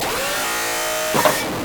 plants-vs-zombies-zombie-hydraulic_25986.mp3